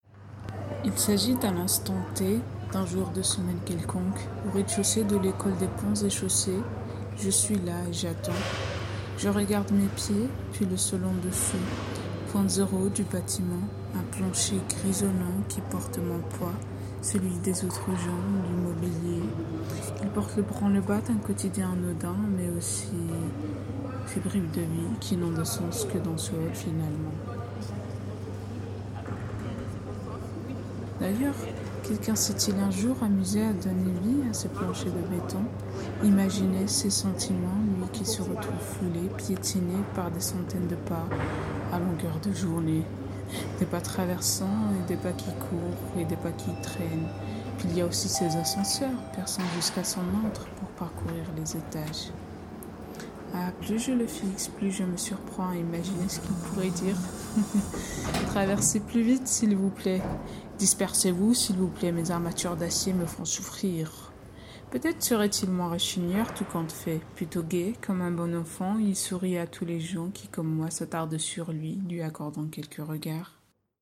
Une minute au RDC de l’école des Ponts et Chaussées - Les villes passagères
minute_au_rdc_des_ponts_et_chaussees_v3.mp3